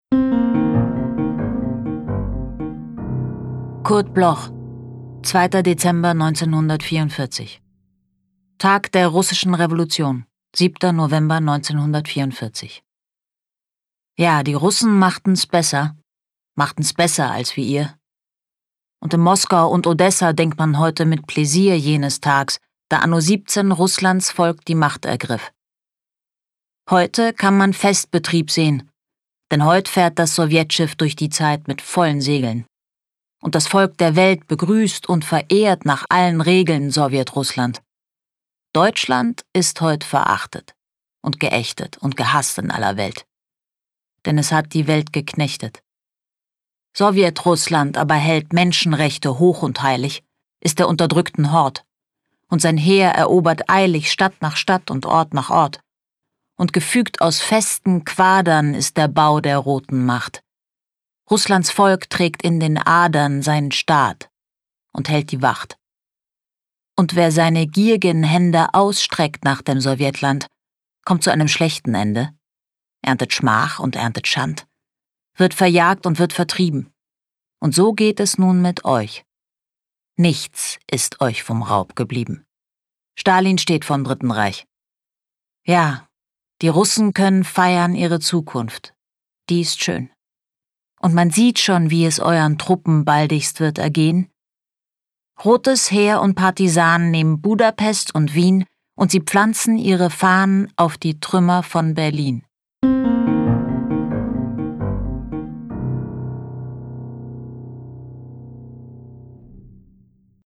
Julia Koschitz (* 1974) is an Austrian actor.
Recording: speak low, Berlin